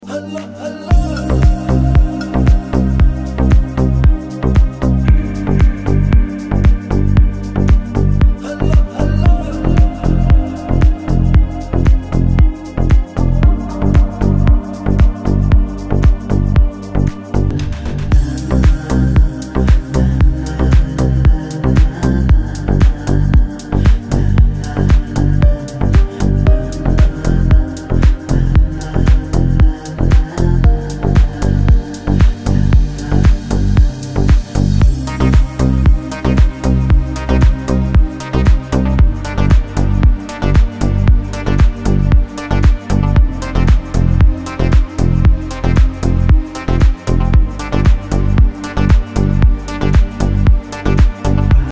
Скачать рингтон Романтический рингтон 2024
спокойные
инструментальные , восточные
Deep House